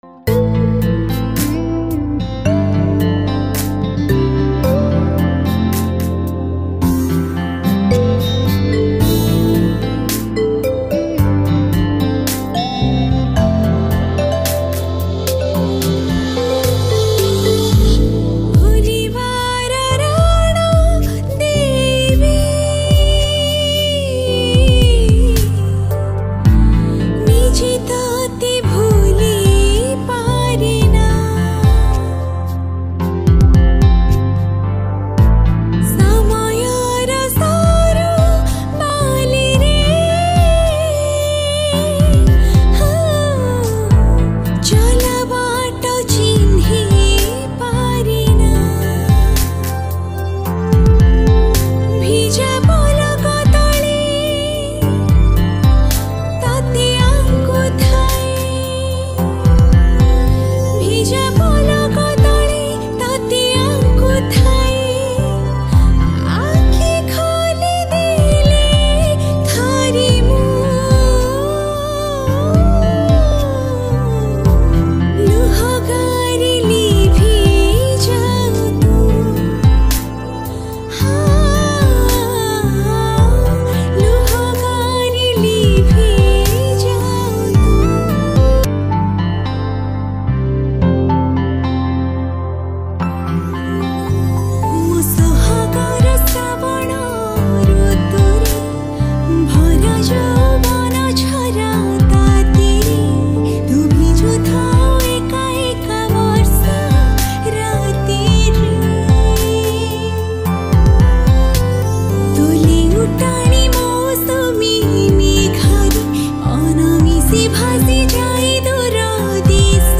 Odia Sad Romantic Song